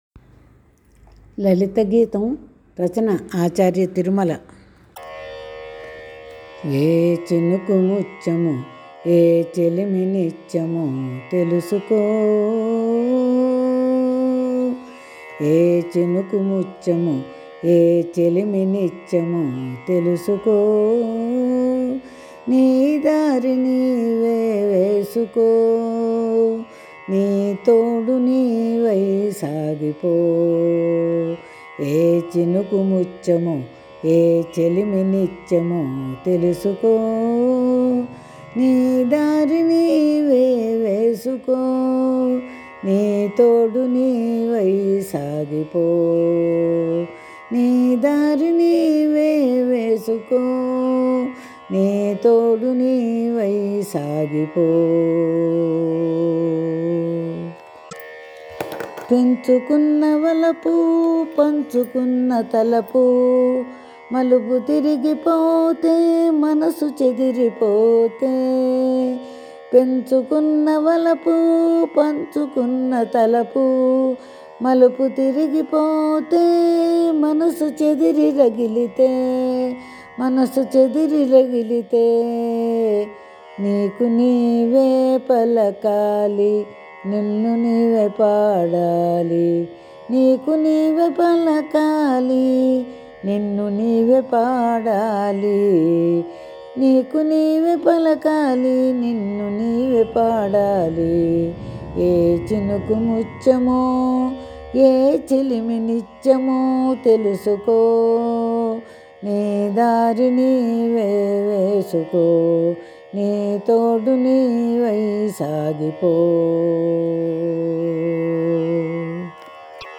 ఆచార్య తిరుమల గారు రచించిన లలిత గీతం-